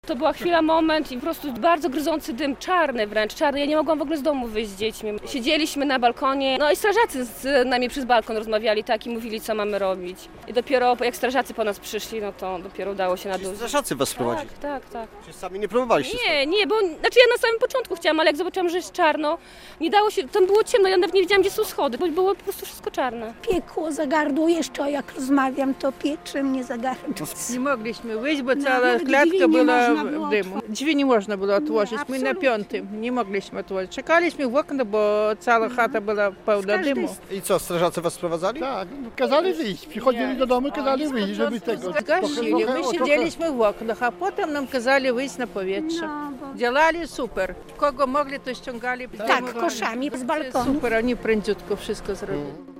Pożar przy ul. Rzemieślniczej - relacja